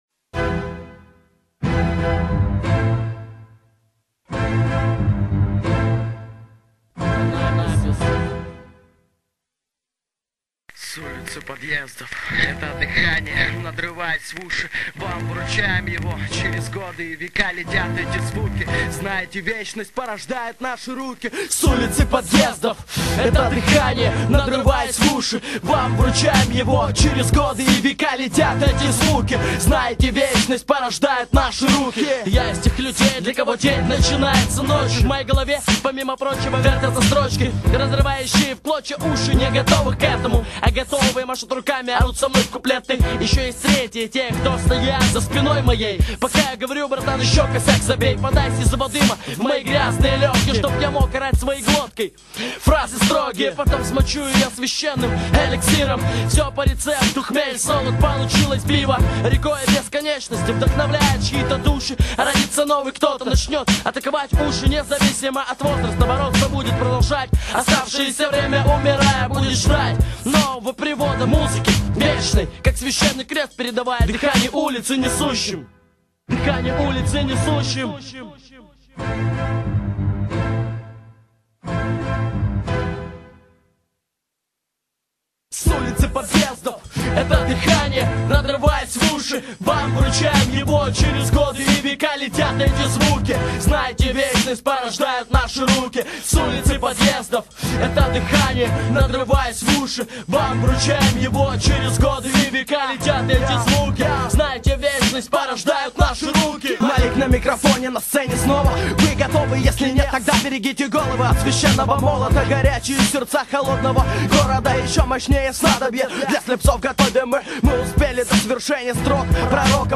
mp3,3689k] Рэп